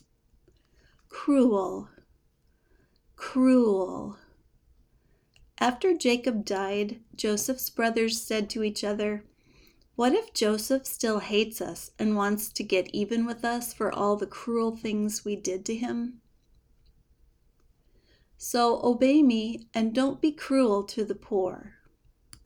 ˈkruəl (adjective)